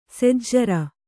♪ sejjara